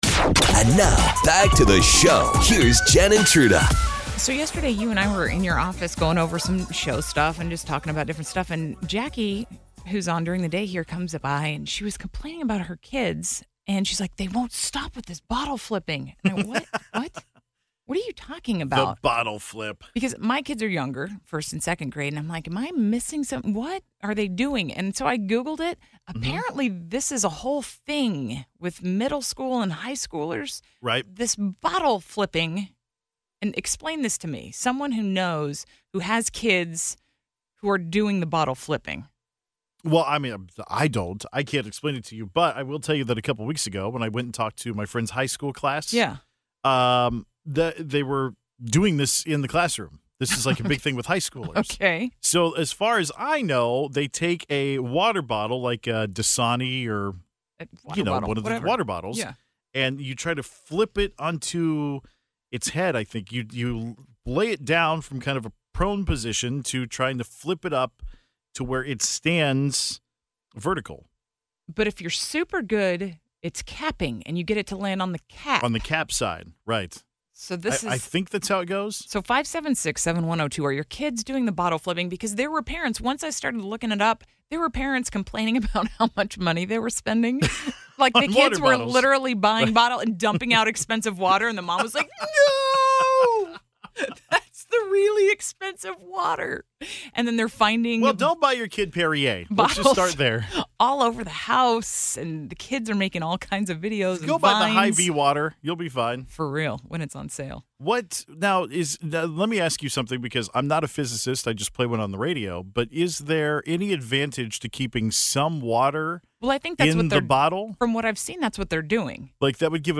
Listeners tried to guess the best TV couples of all time.